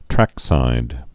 (trăksīd)